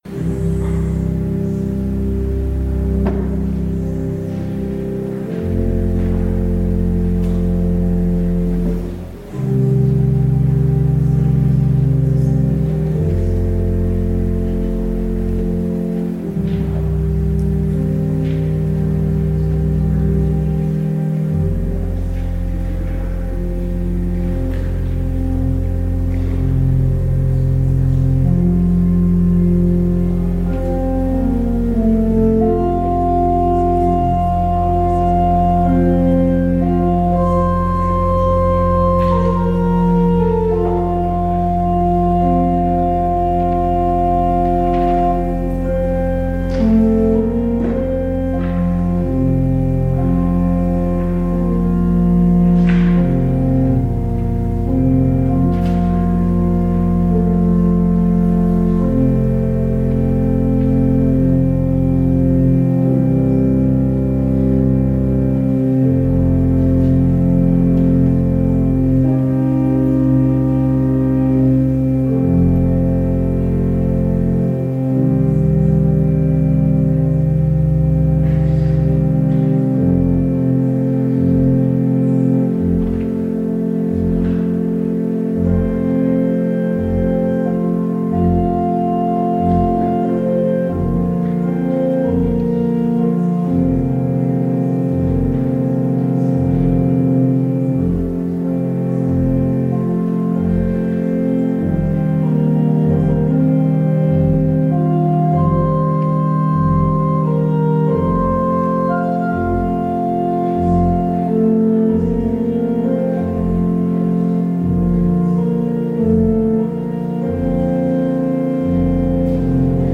Due to technical problems, the recording of the service from the eagle and clergy mikes was unavailable, so the entire service was recorded as ambient sound.
Audio recording of the 10am service